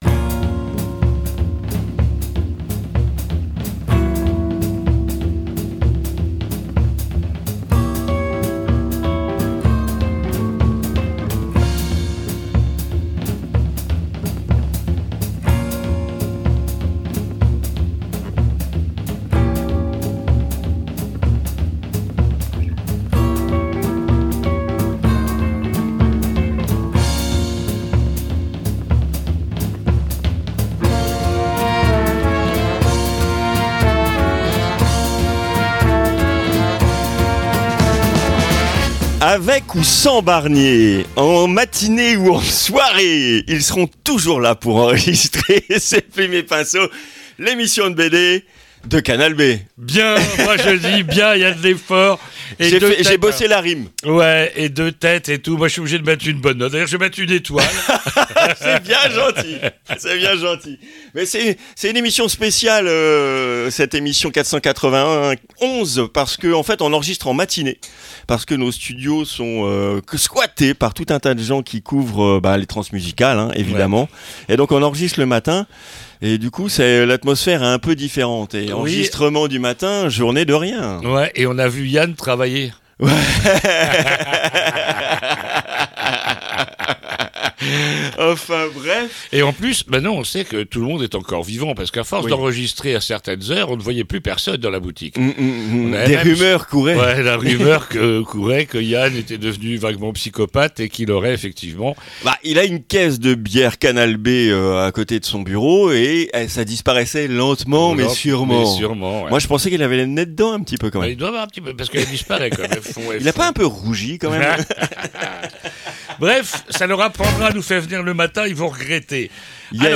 II - INTERVIEW